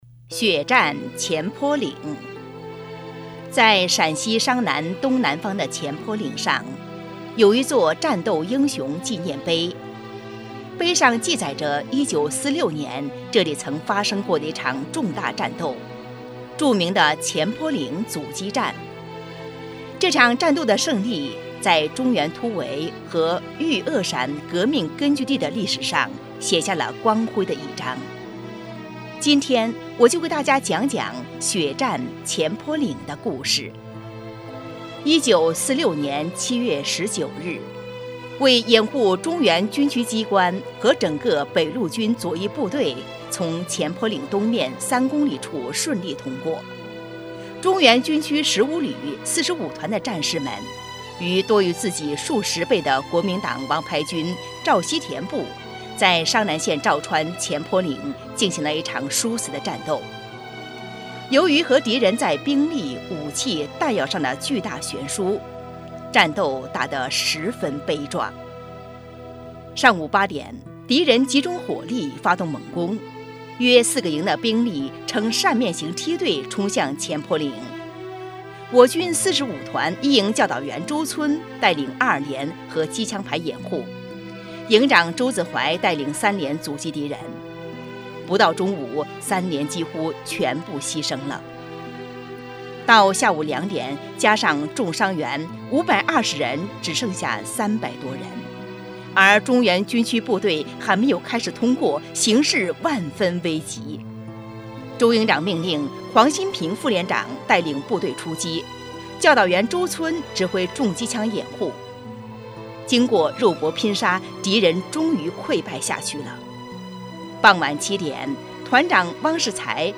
【红色档案诵读展播】血战前坡岭